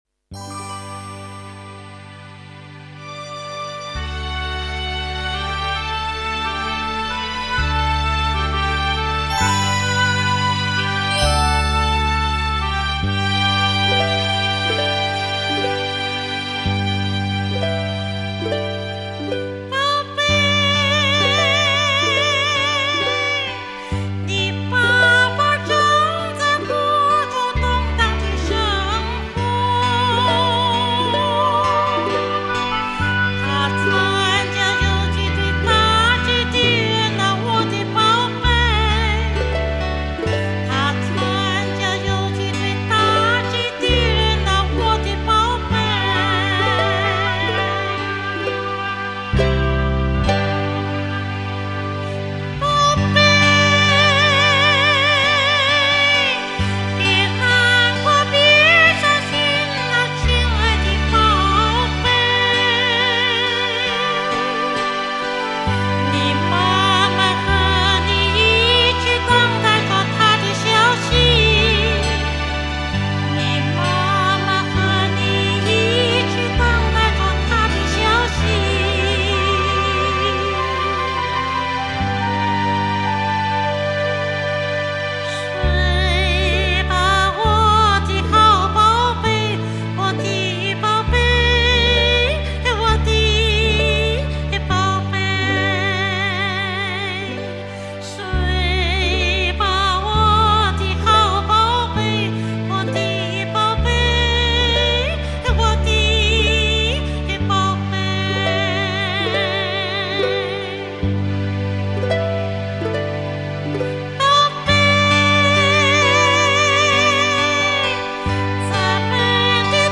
印度尼西亚民歌